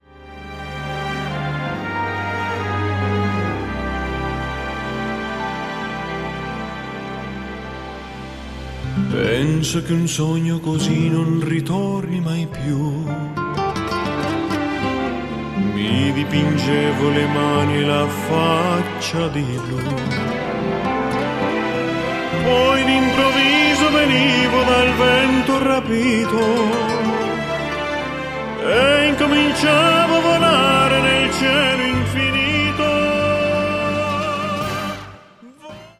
with classical tenor and orchestra